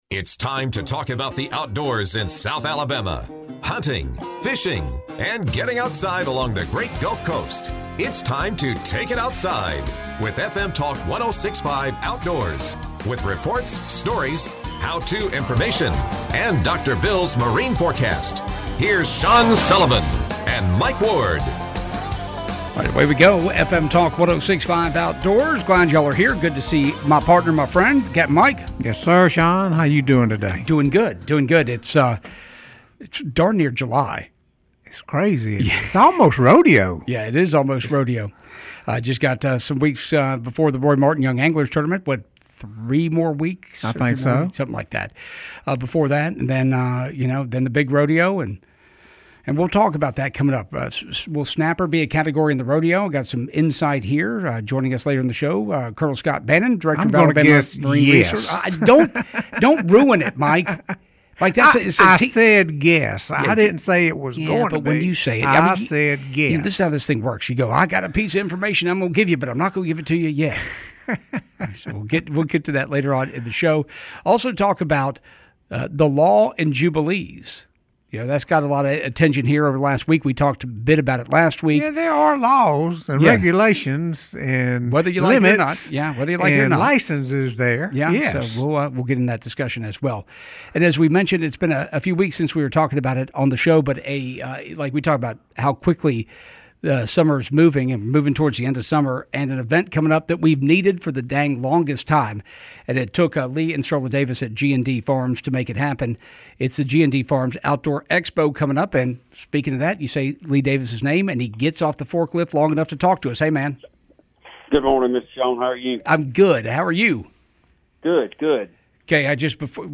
talk with Director of Alabama Marine Resources Scott Bannon.